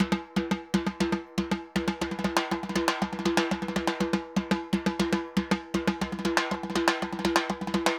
Timba_Candombe 120_3.wav